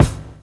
WU_BD_306.wav